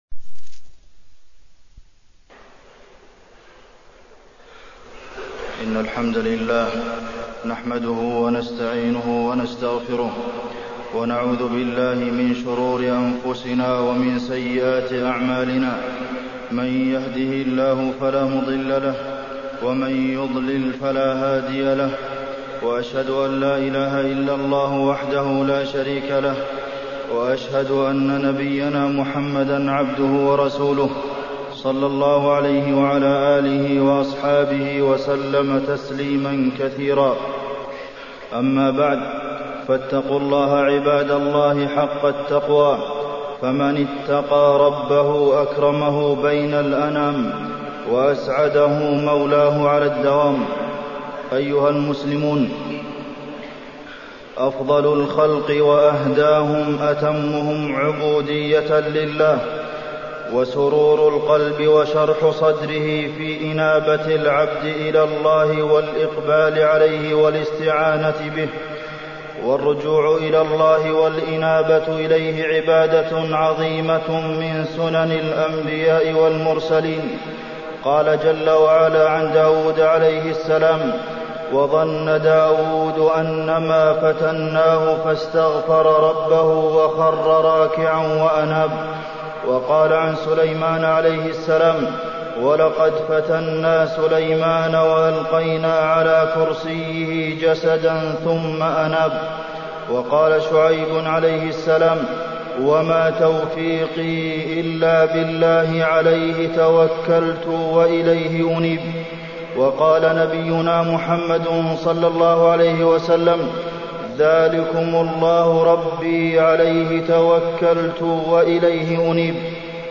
تاريخ النشر ٩ محرم ١٤٢٦ هـ المكان: المسجد النبوي الشيخ: فضيلة الشيخ د. عبدالمحسن بن محمد القاسم فضيلة الشيخ د. عبدالمحسن بن محمد القاسم الإنابة إلى الله